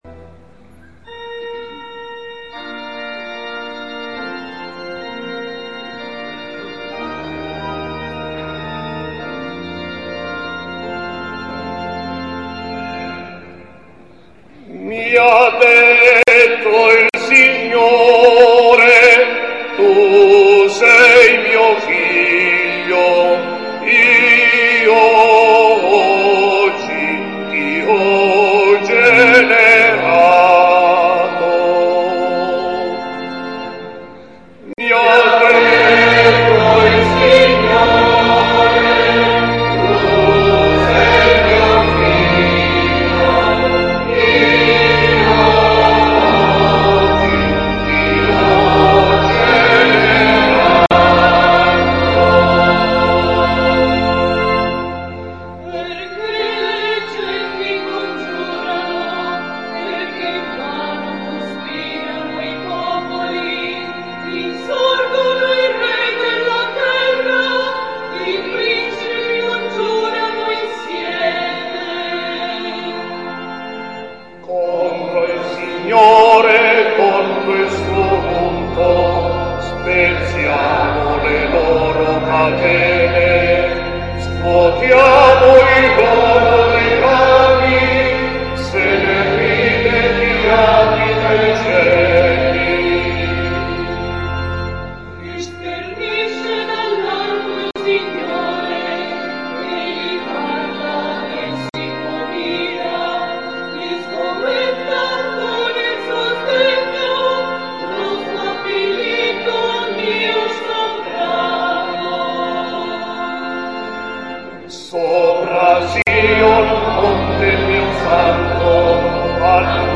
La Messa della Notte di Natale nella Cattedrale di Cefalù